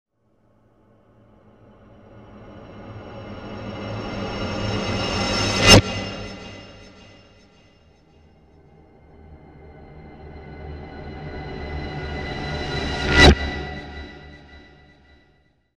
creepy-sound